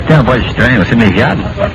voz-estranha.mp3